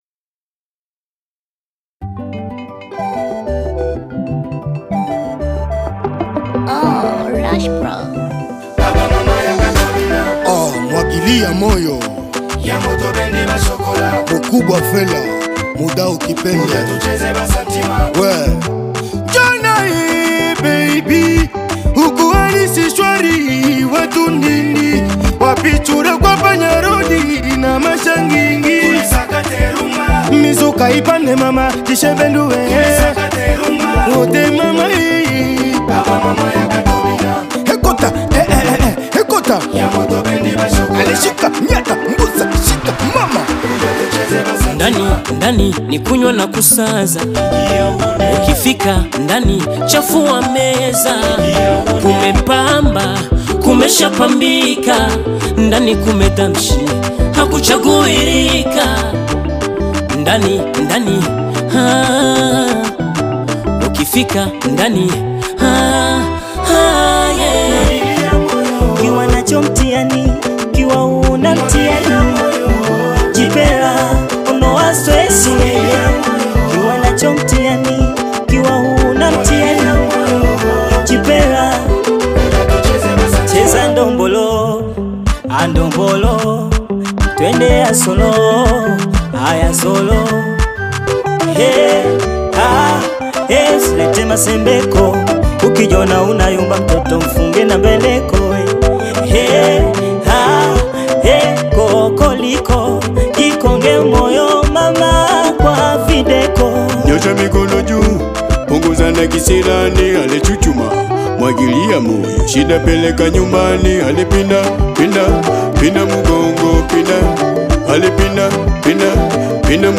vibrant Afro-pop single